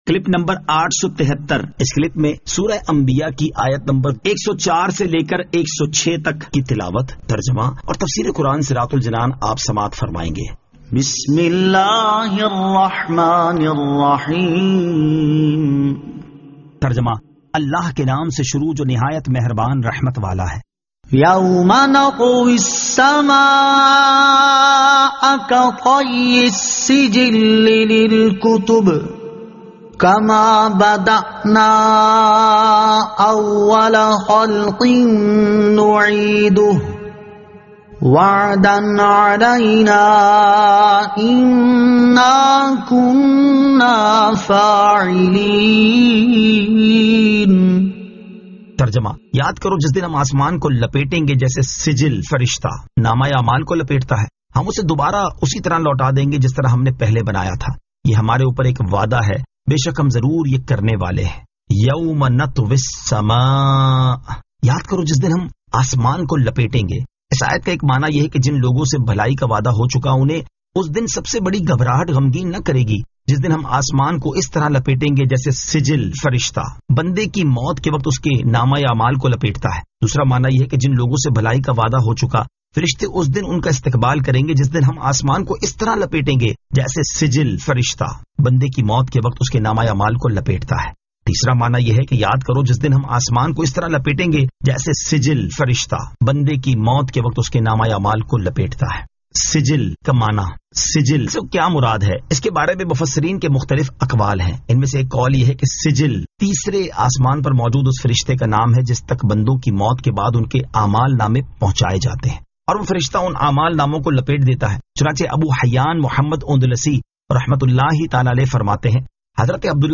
Surah Al-Anbiya 104 To 106 Tilawat , Tarjama , Tafseer